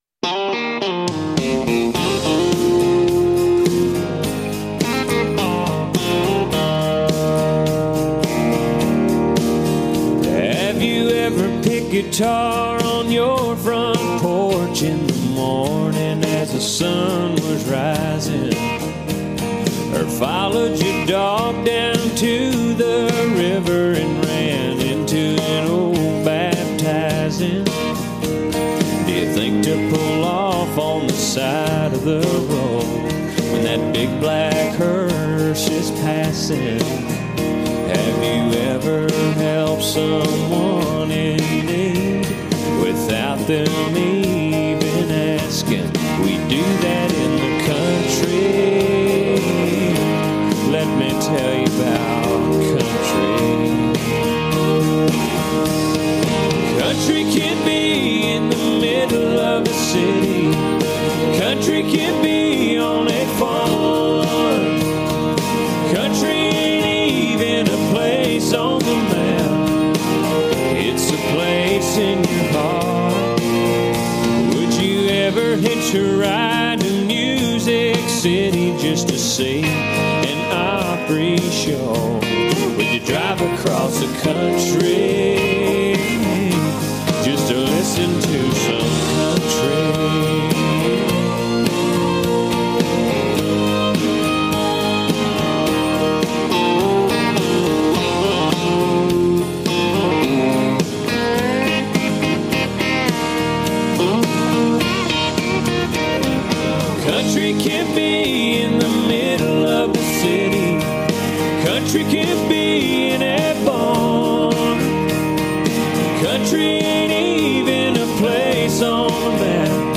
LISTEN: We hear from country music star Mo Pitney.